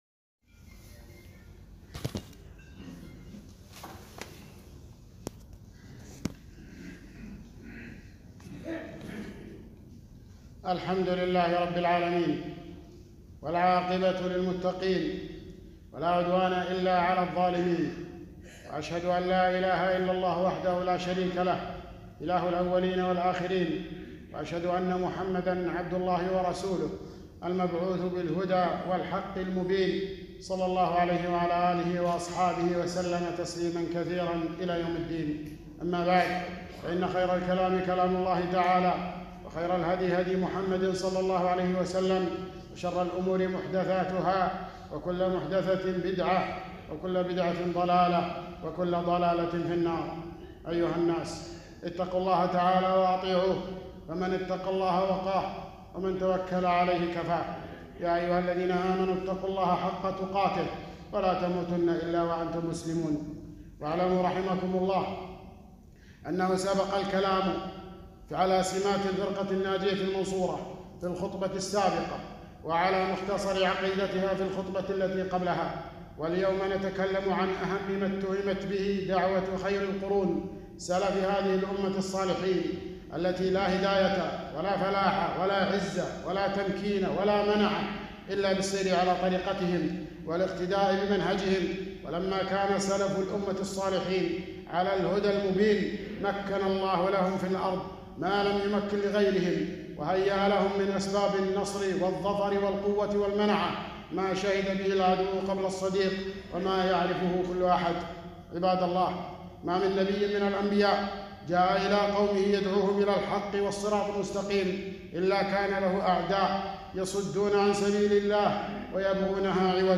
خطبة - براءة السلفية من التهم الوبية